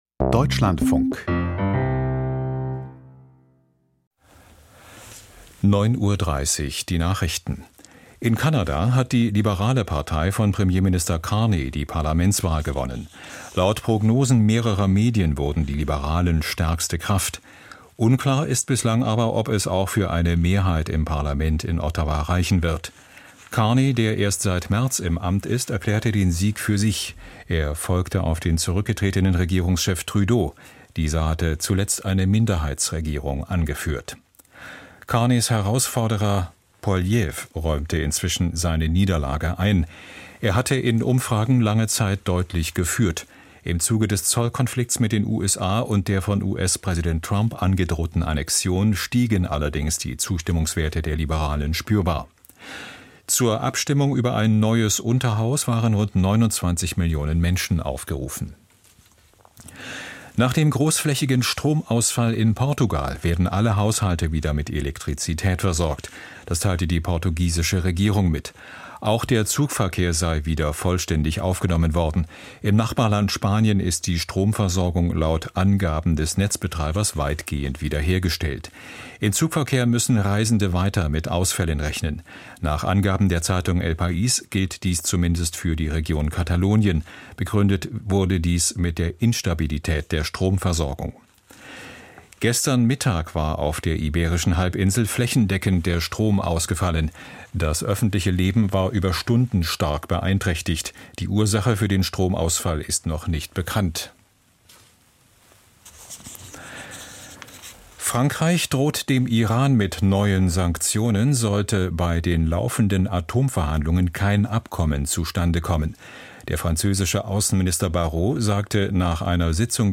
Die Deutschlandfunk-Nachrichten vom 29.04.2025, 09:30 Uhr